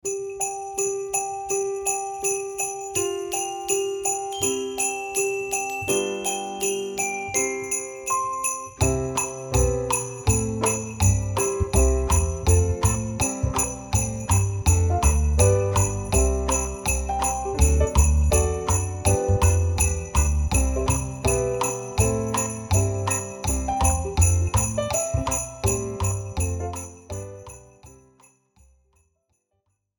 Besetzung: 1-2 Altblockflöten